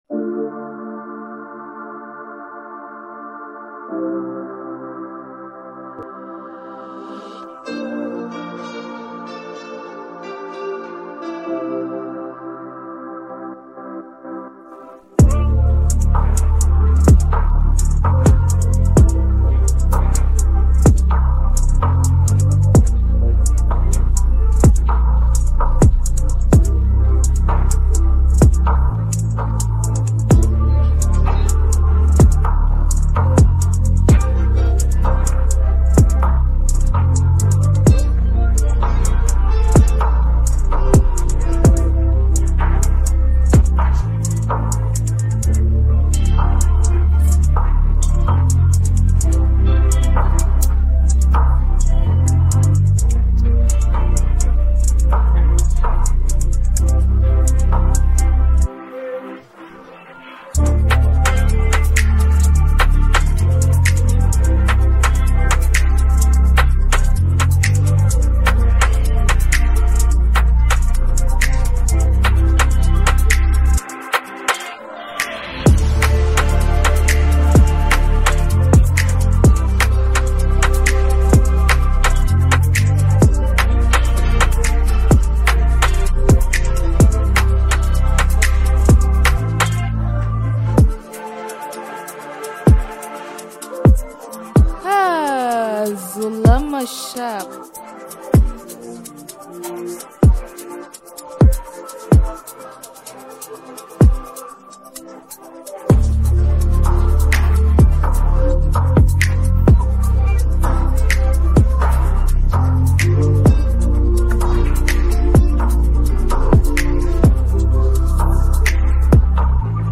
hiphop trap beats
hip hop
rap beats